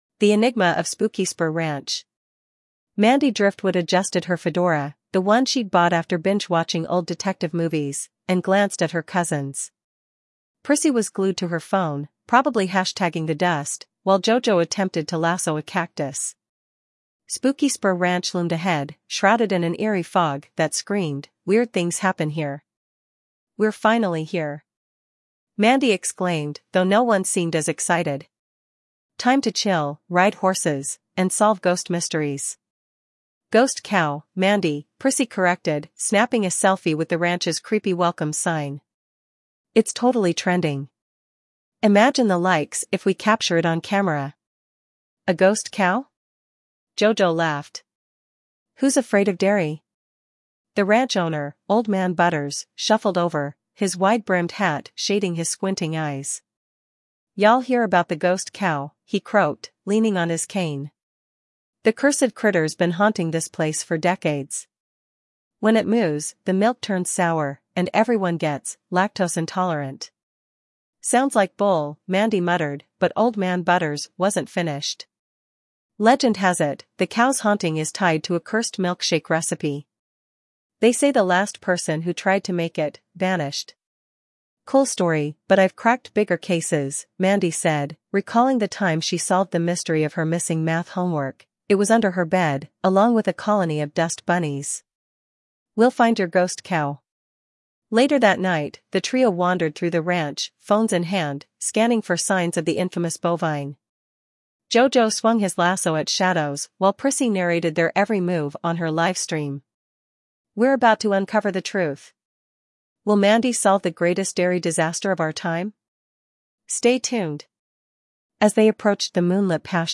Ten Parodic Short Story Mysteries